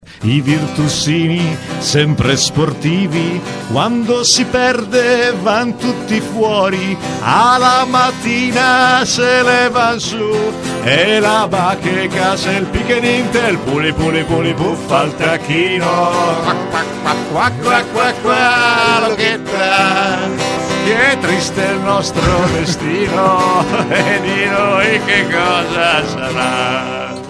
Oggi in radio
chitarra